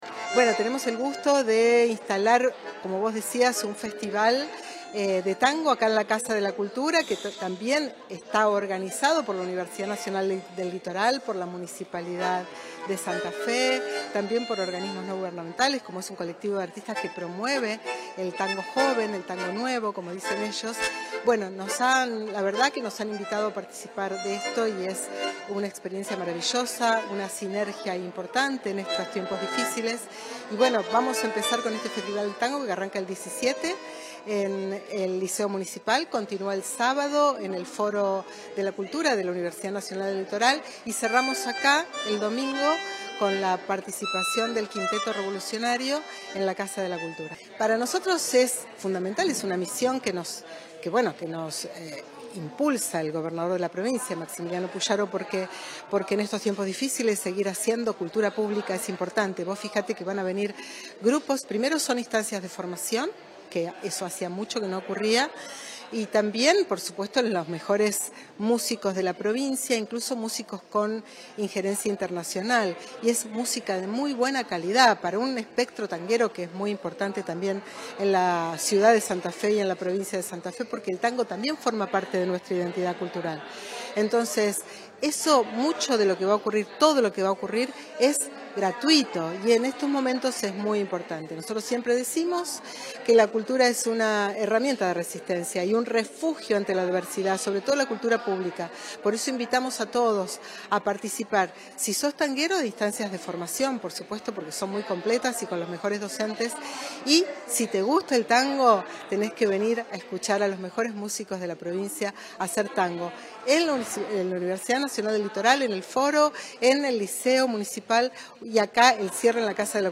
Presentación del Festival de Tango de Santa Fe